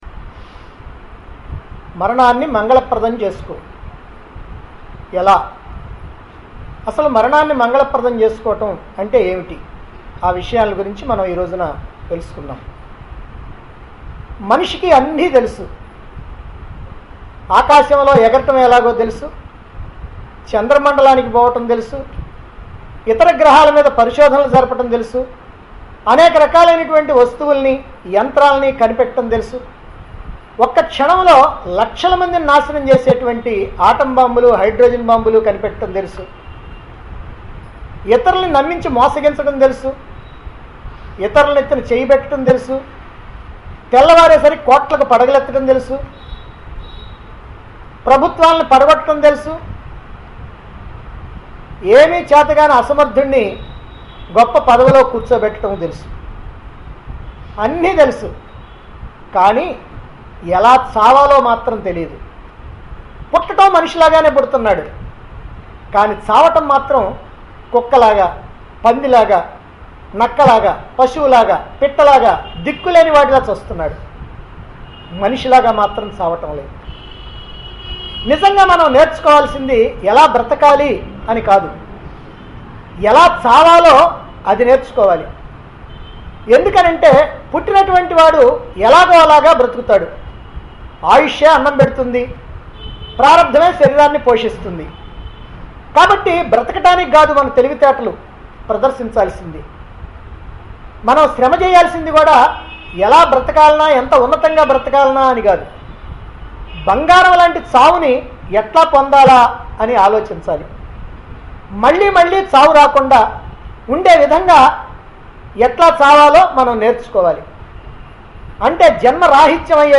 Aadhyatmikam - Marananni Mangalapradam Chesko Discourses Recorded On 13-NOV-2010 Discourse Conducted At Chilakaluripet, Guntur Dt. Andhra Pradesh.